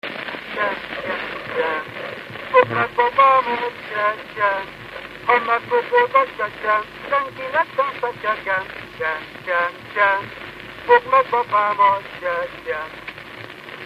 Alföld - Szatmár vm. - Nábrád
ének
Stílus: 7. Régies kisambitusú dallamok